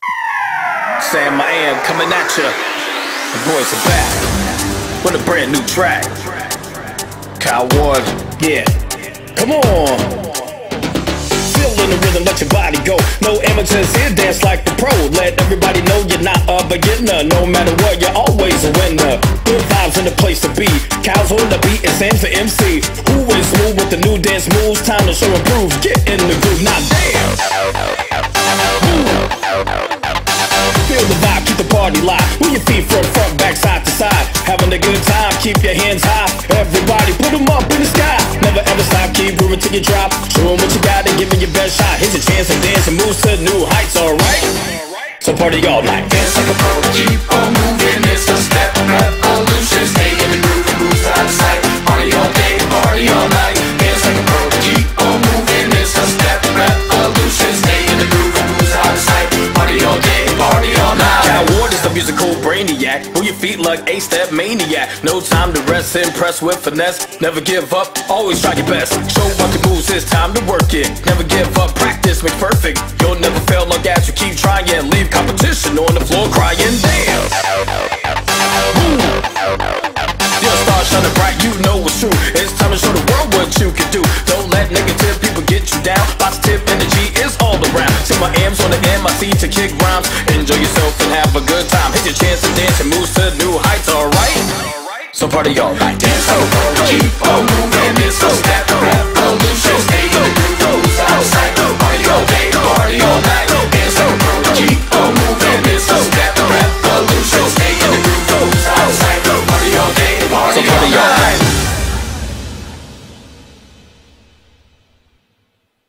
BPM125
Audio QualityPerfect (High Quality)
Comments[DANCE GROOVE]